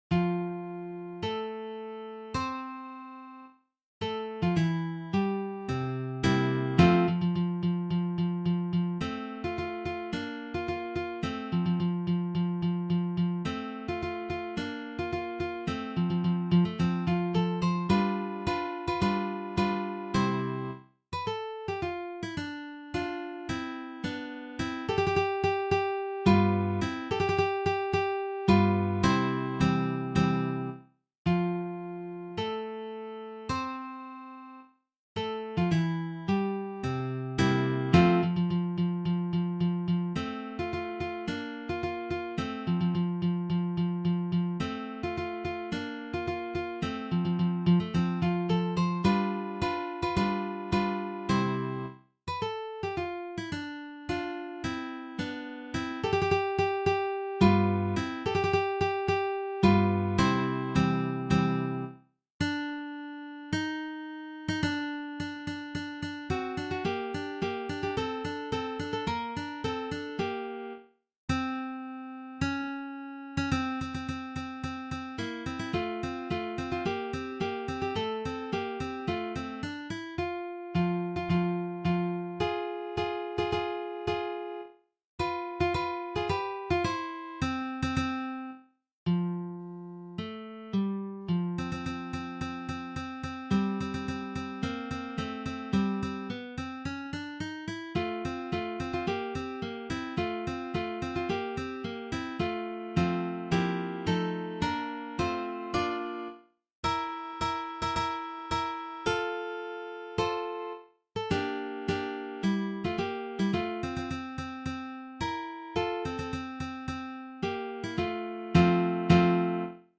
arrangement for three guitars